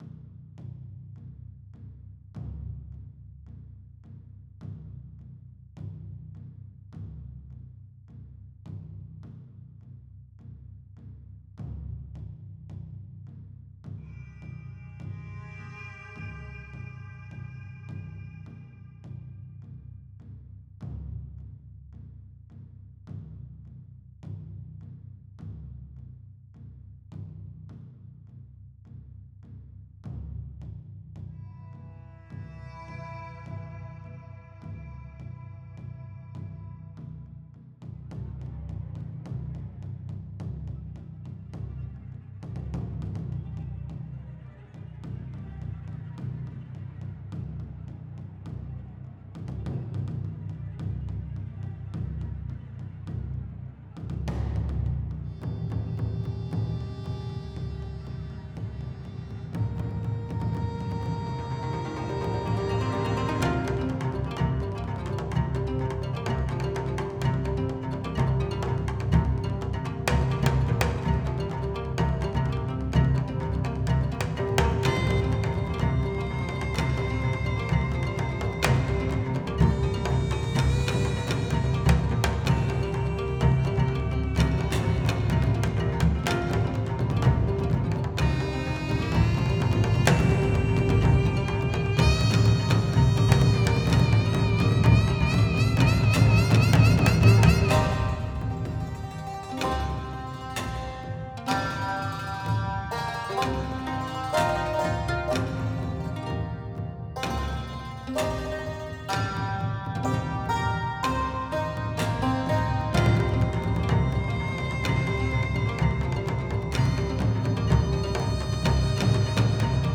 Combat Music